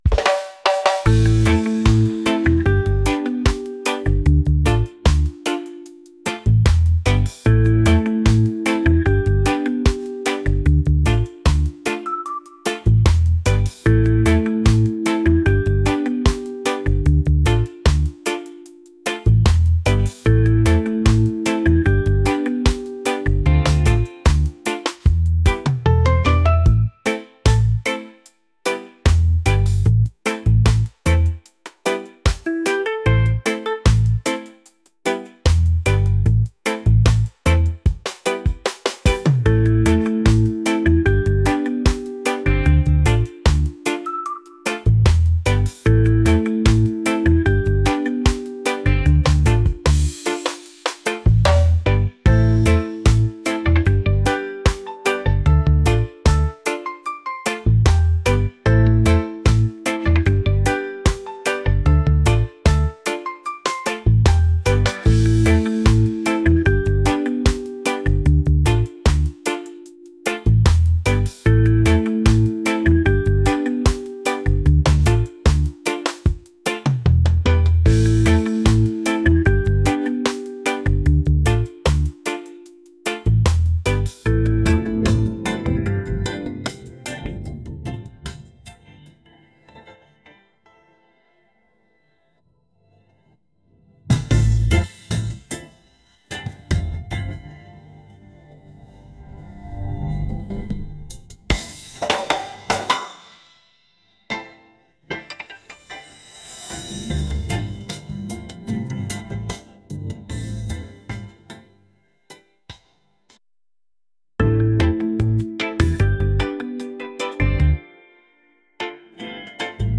laid-back | reggae | catchy